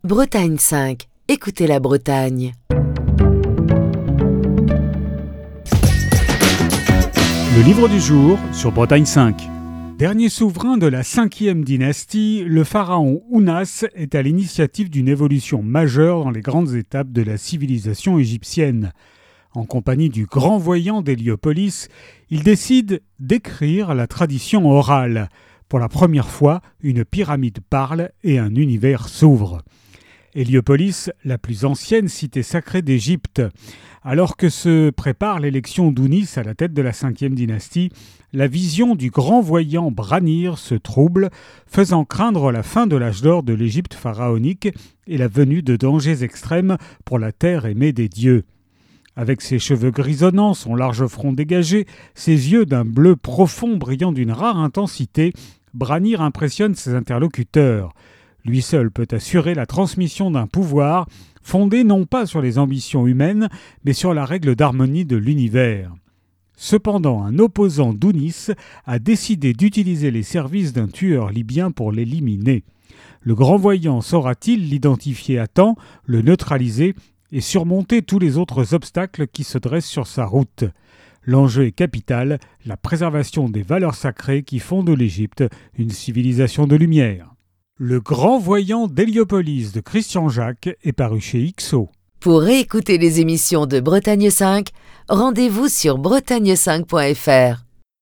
Chronique du 8 décembre 2025.